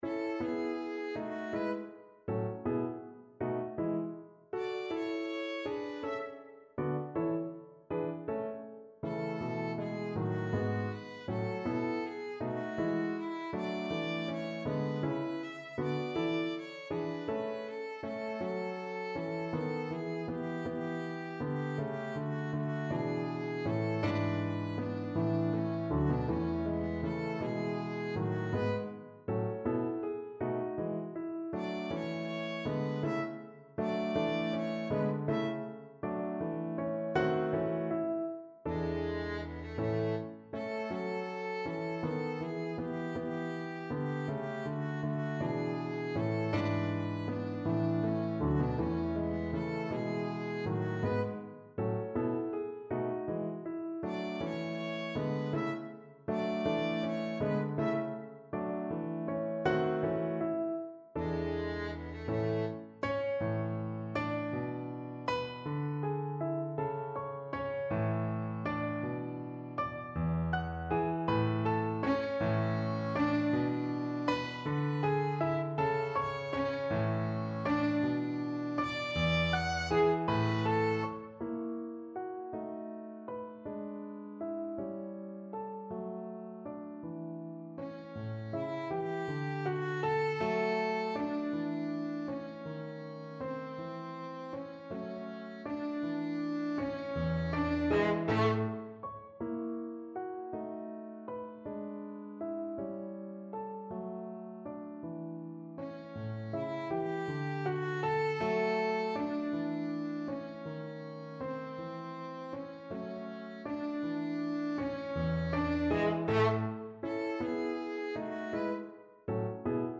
Classical Beethoven, Ludwig van Moonlight Sonata (2nd Movement) Violin version
Violin
A major (Sounding Pitch) (View more A major Music for Violin )
II: Allegretto =160
3/4 (View more 3/4 Music)
Classical (View more Classical Violin Music)